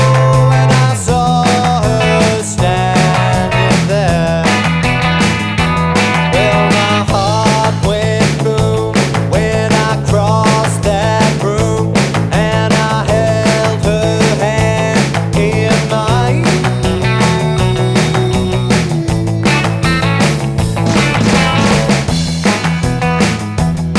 no Backing Vocals Pop (1960s) 2:56 Buy £1.50